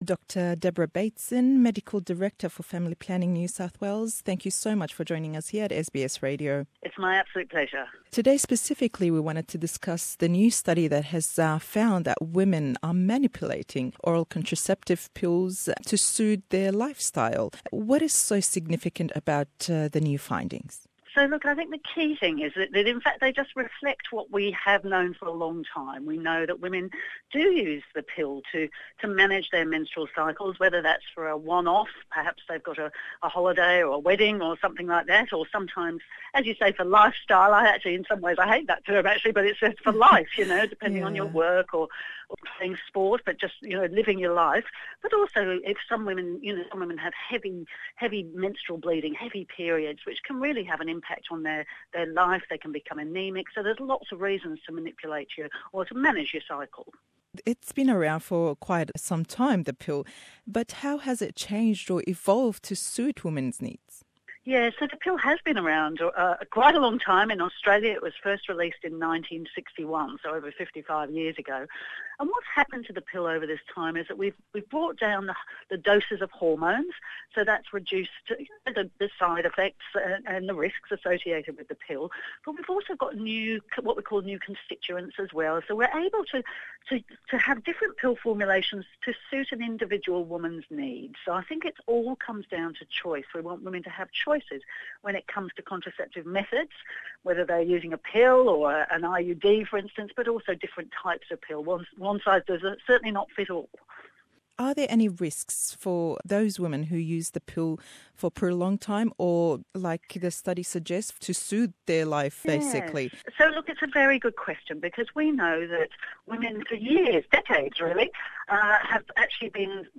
le em hevpeyvîn pirsyarî gringî ew dozînewe ye y lê deken, pêşkewtinî ew hebane û hellbijartinekan bo jinan.